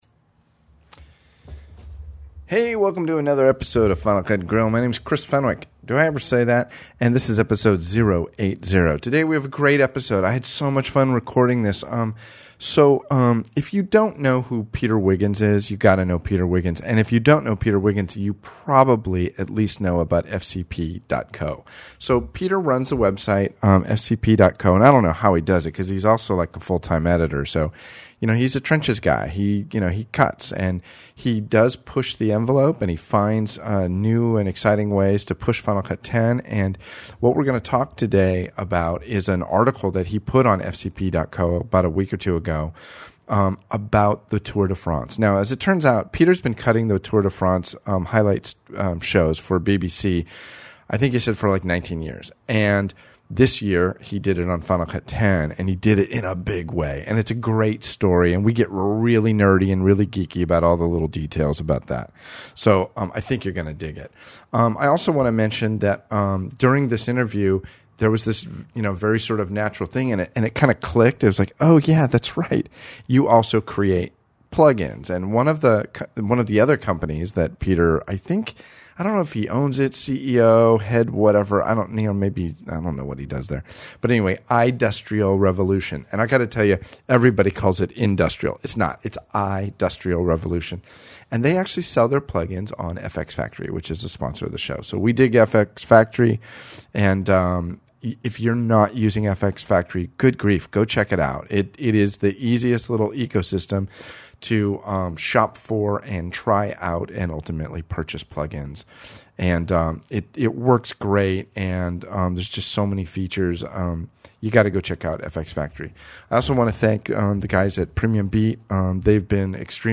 I think you’ll love this interview.